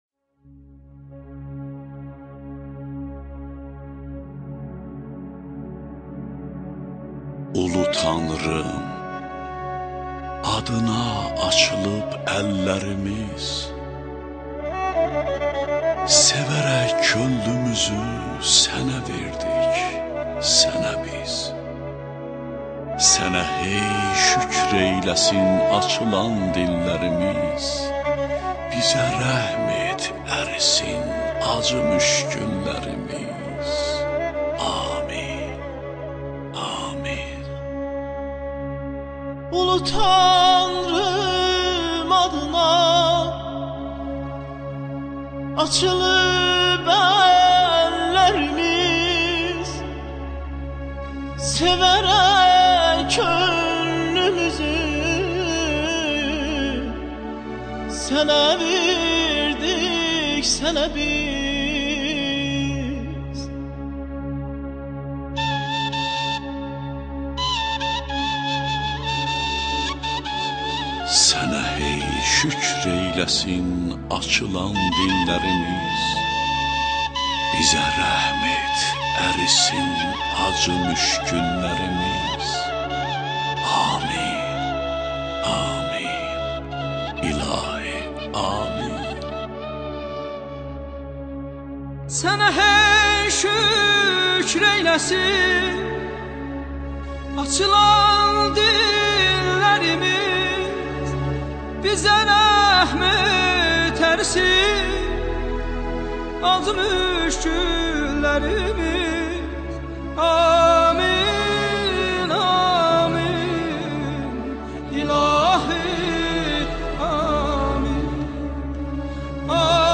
ŞEİRLƏR
AKTYORLARIN İFASINDA
"AMIN" - Musiqi: Əməkdar İncəsənət Xadimi, Bəstəkar-Tahir Əkbər, Sözləri: Xalq Şairi - Bəxtiyar Vahabzadə, Bədii qiraət - Xalq Artisti - İlham Əsgərov, Musuqunu ifa edirlər: III TV Muğam Müsabiqəsinin laureatları.